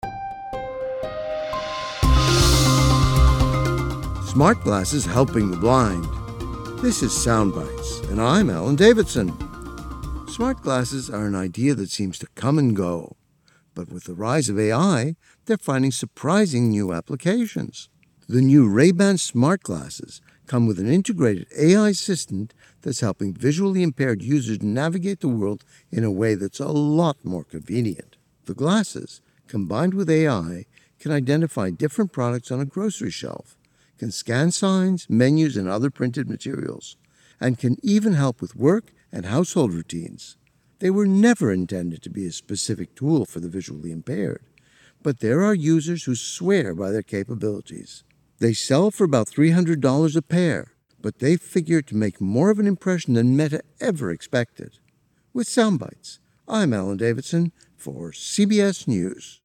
Sound*Bytes is an award-winning national radio show feature distributed to the over 500 stations on the CBS News network.